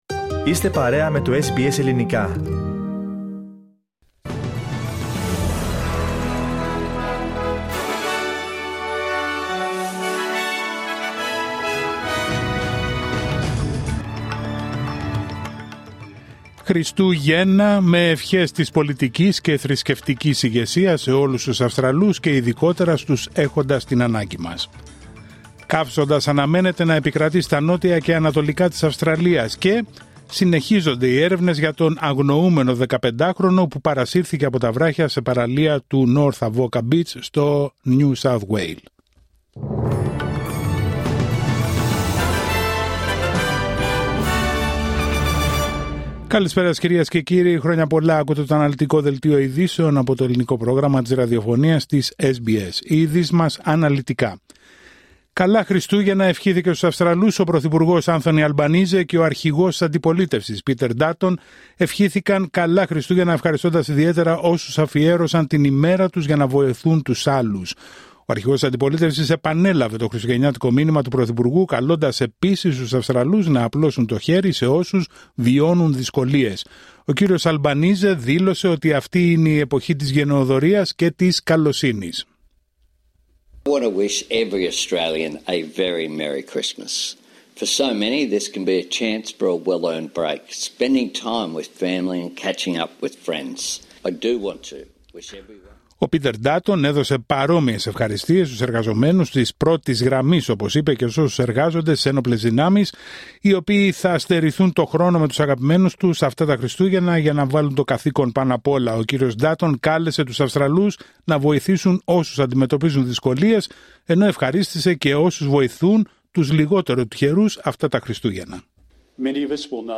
Δελτίο ειδήσεων Τετάρτη 25 Δεκεμβρίου 2024